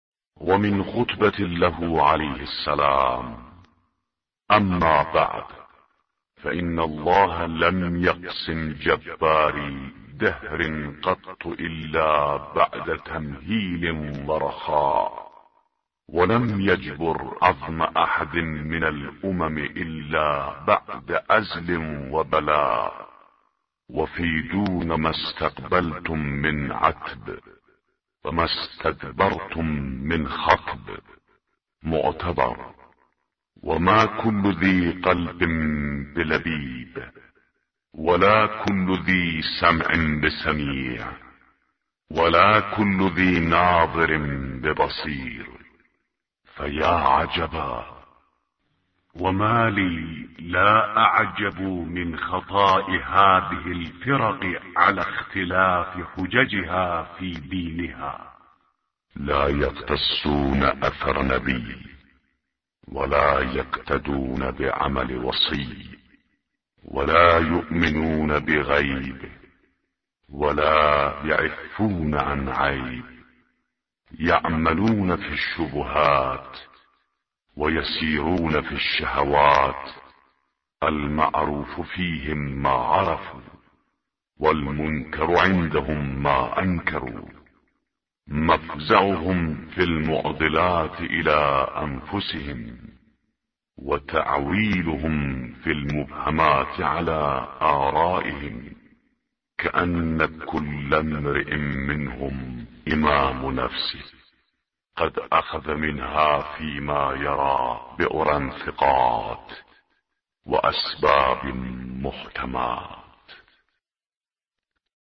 به گزارش وب گردی خبرگزاری صداوسیما؛ در این مطلب وب گردی قصد داریم، خطبه شماره ۸۸ از کتاب ارزشمند نهج البلاغه با ترجمه محمد دشتی را مرور نماییم، ضمنا صوت خوانش خطبه و ترجمه آن ضمیمه شده است: